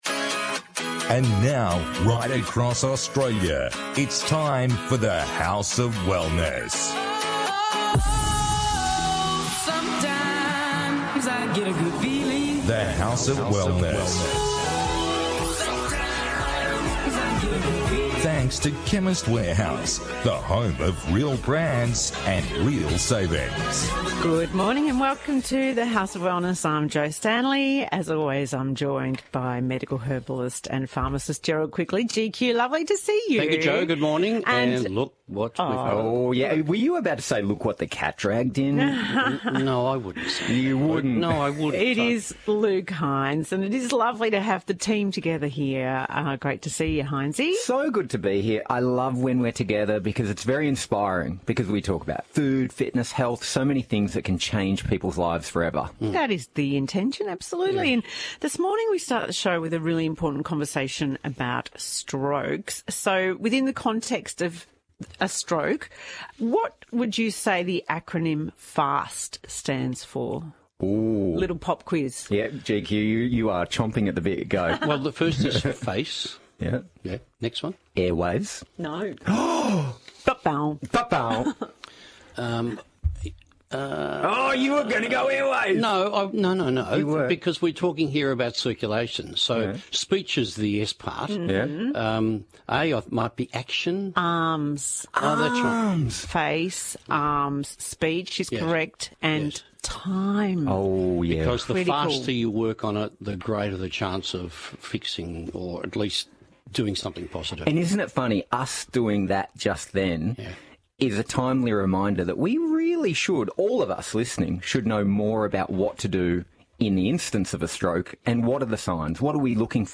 On this week’s The House of Wellness radio show: